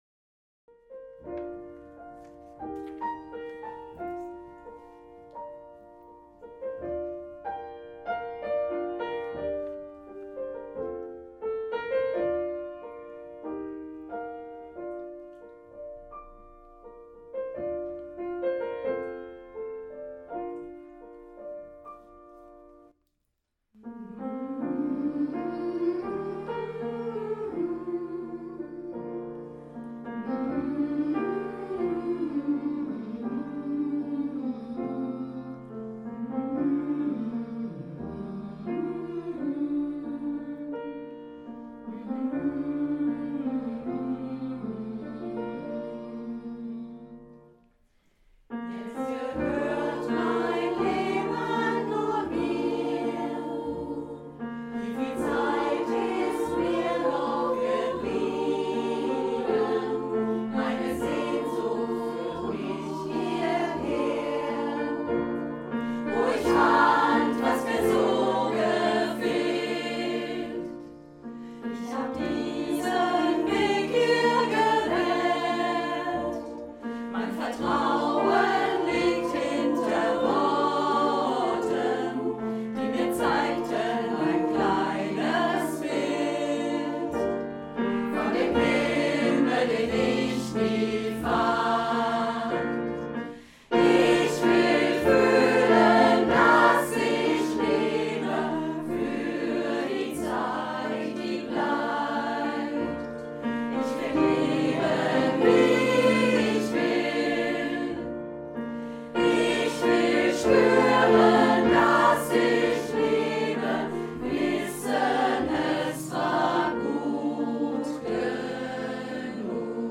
Der Frauenchor der Chrogemeinschaft besteht seit fast 50 Jahren und singt Lieder aus allen Zeiten und Genres.
Wir sind ein beständiger Chor, bestehend aus etwa 35 aktiven Sängerinnen zwischen 30 und 90 (!) Jahren, von denen einige schon seit 20, 30 und 40 Jahren zusammen singen.